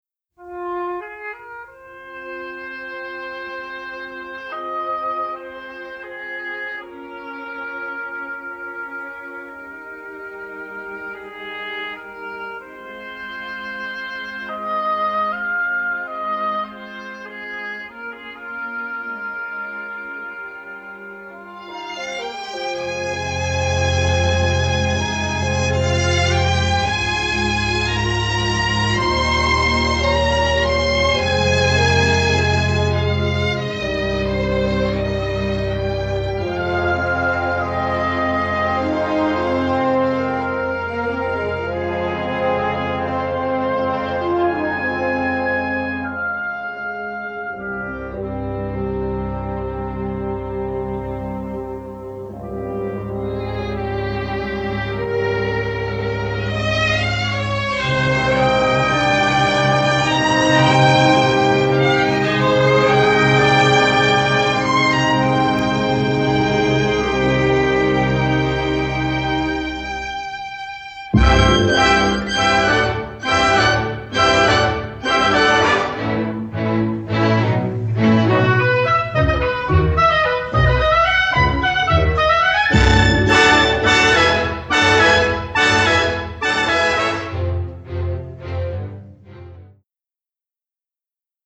western score
complete score mastered in mono from print takes